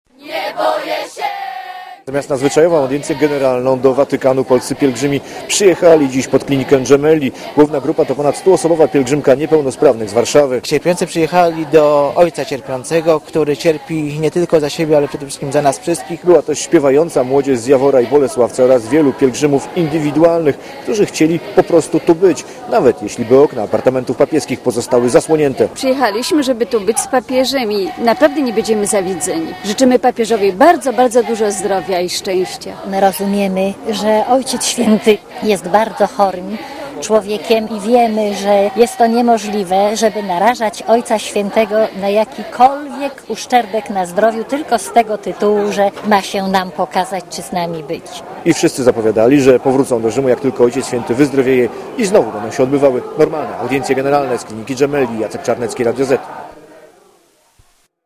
W tym samym czasie przed szpitalem śpiewało i modliło się o zdrowie papieża ponad dwustu polskich pielgrzymów.
Relacja reportera Radia ZET
papiezpielgrzymispiewaja.mp3